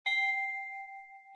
chimes.ogg